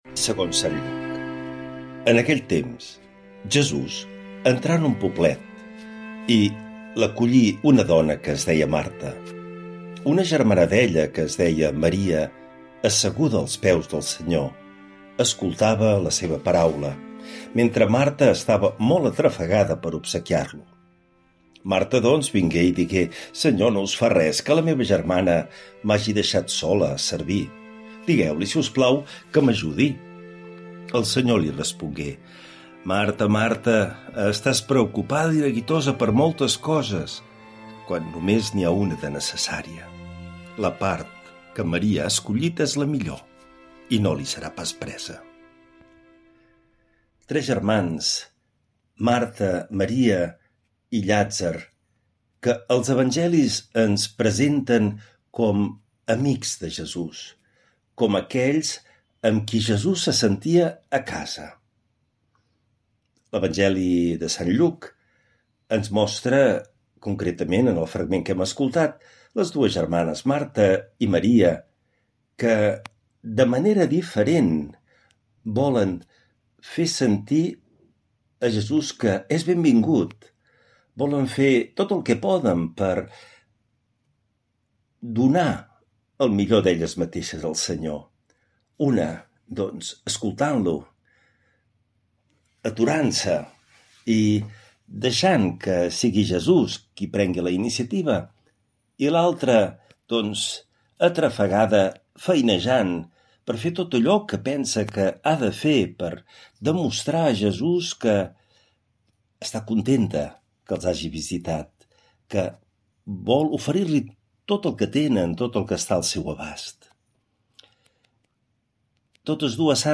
L’Evangeli i el comentari de dimarts 29 de juliol del 2025.
Lectura de l’evangeli segons sant Lluc